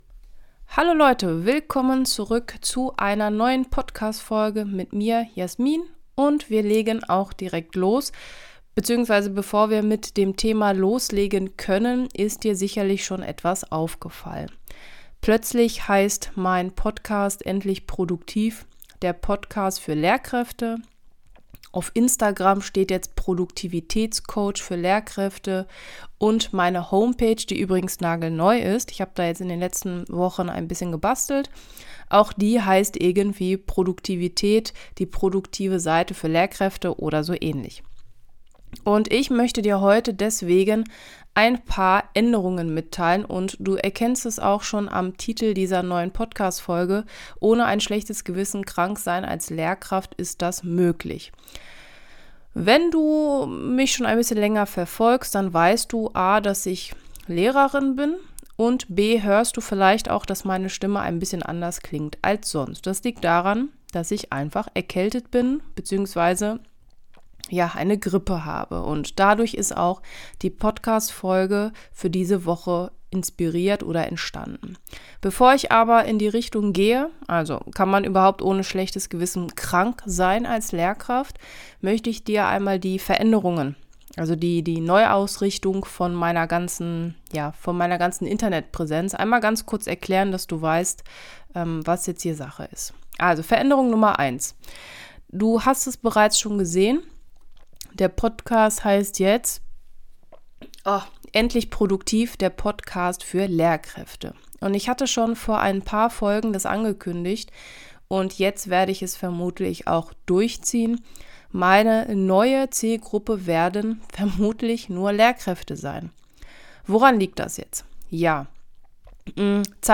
Warum das so ist und wieso meine Stimme wie Darth Vader klingt, das erfährst du in der heutigen Folge.